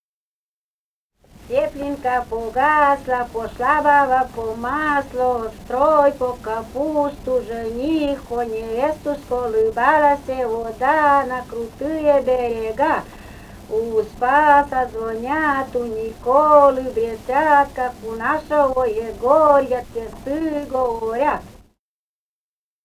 «Теплинка погасла» (прибаутка).